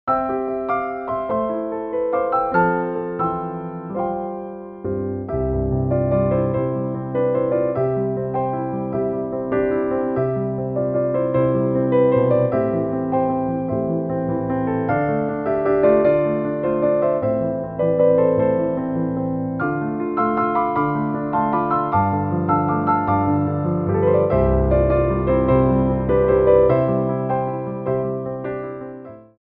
3/4 (16x8)